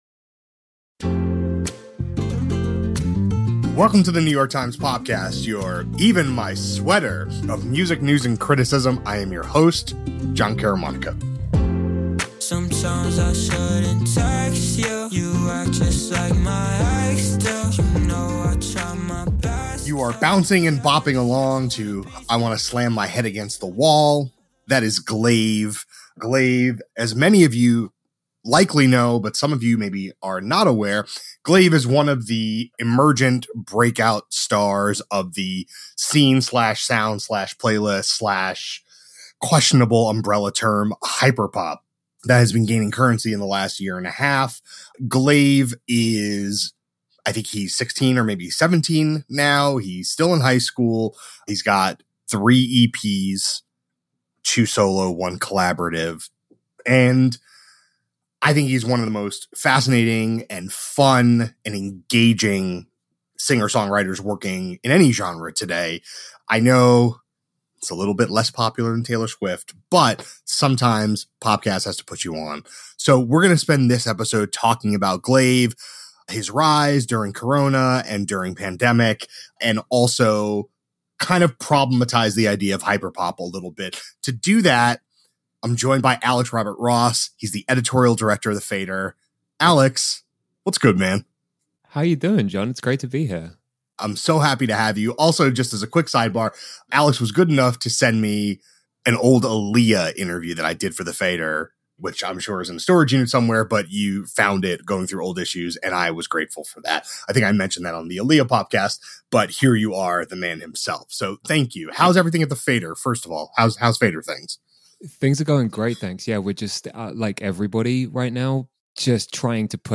A conversation about one teen’s rapid ascent, and what the future might hold for a singer and sound evolving in real time.